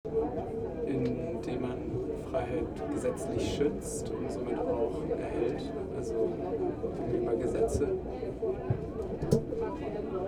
Standort der Erzählbox:
Ein Fest für die Demokratie @ Bundeskanzleramt, Berlin